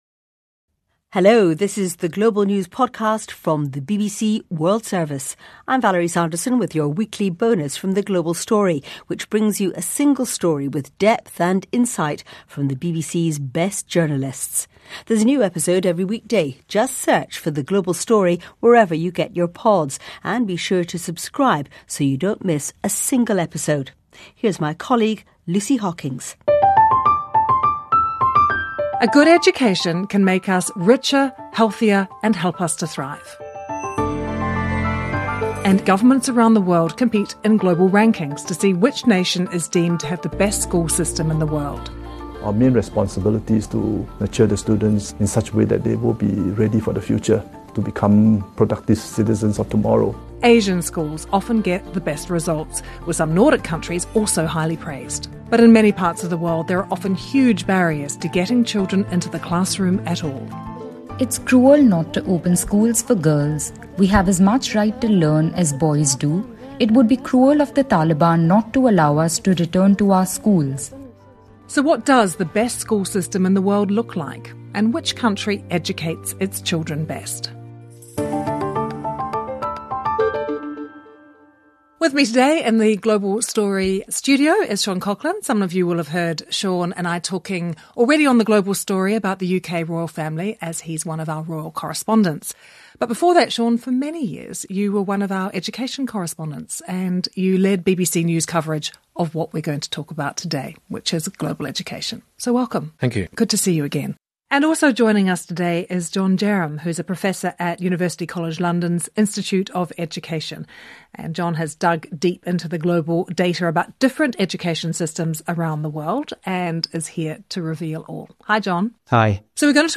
The best stories, interviews and on the spot reporting from around the world including highlights from News hour, The World Today and World Briefing. Up to 30 minutes compiled twice a day from the 24 hour News coverage from the BBC World Service.